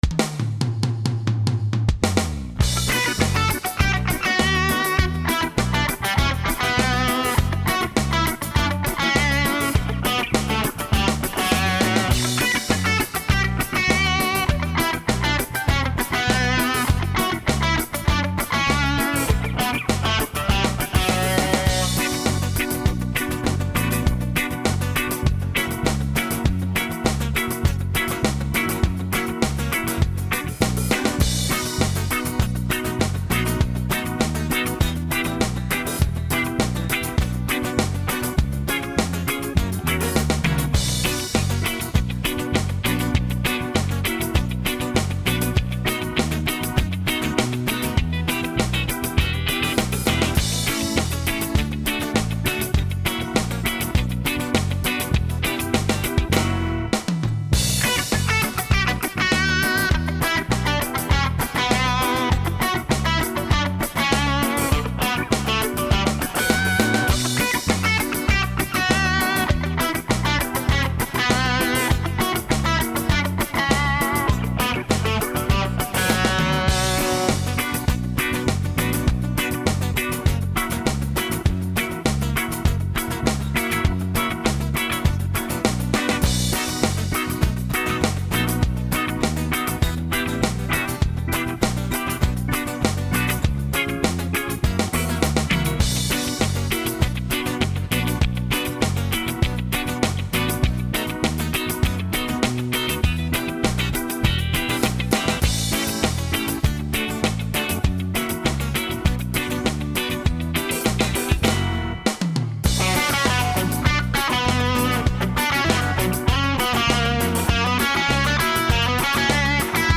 минусовка версия 238046